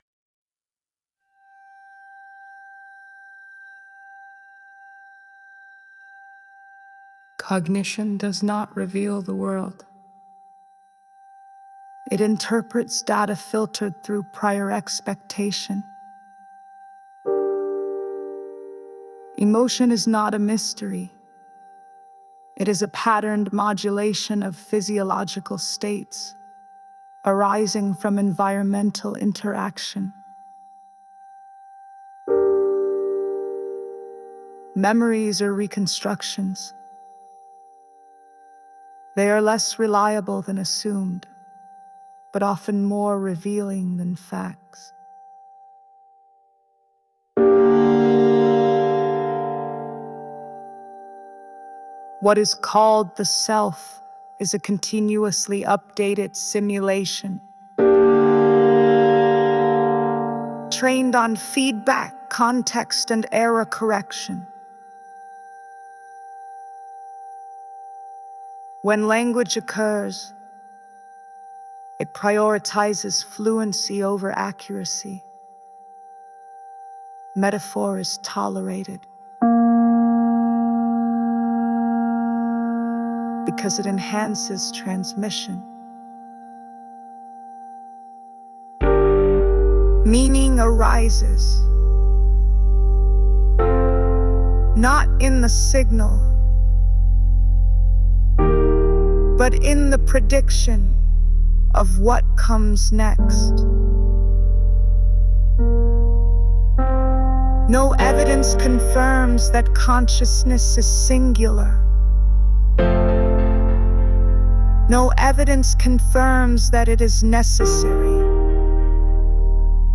Neurosynth’s voice
However, she was allowed to sing!
Written for calm female narration with minimal emotional inflection, set over ambient background